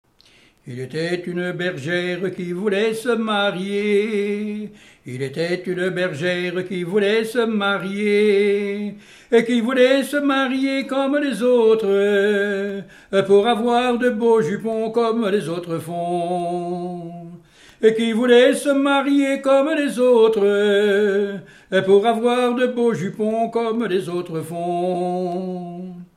Genre laisse
chansons traditionnelles